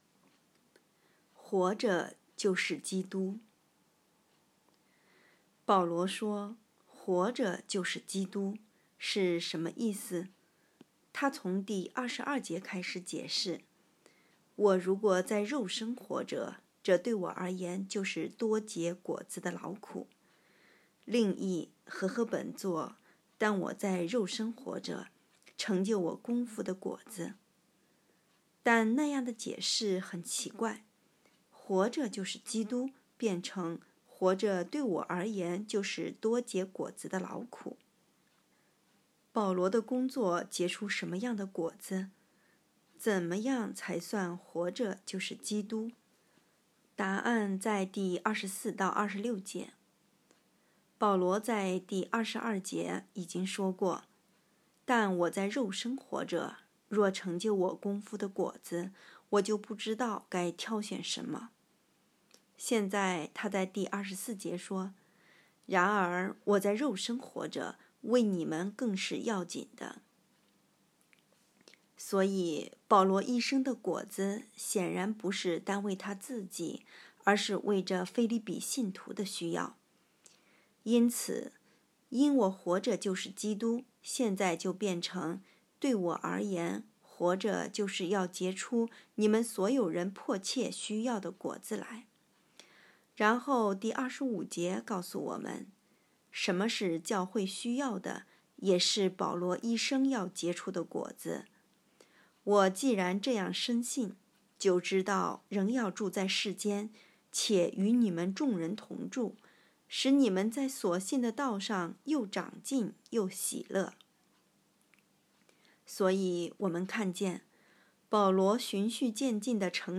2023年12月7日 “伴你读书”，正在为您朗读：《活出热情》 音频 https